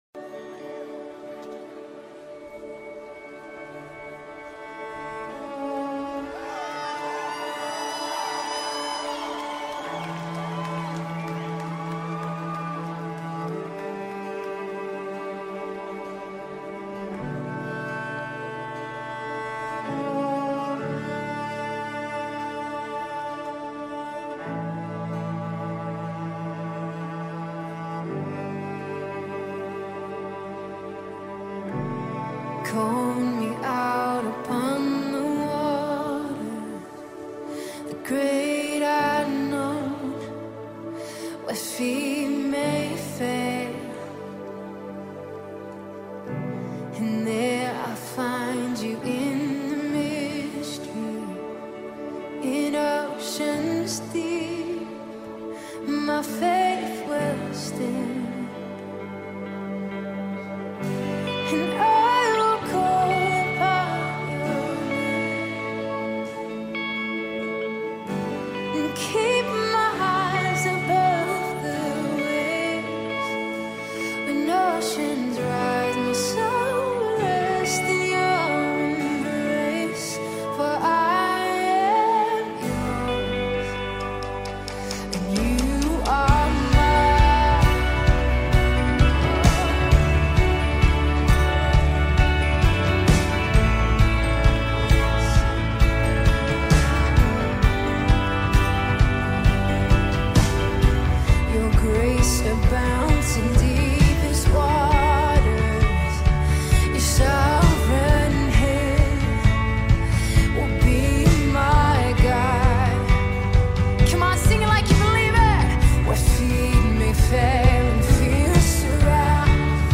Australian-based Christian worship band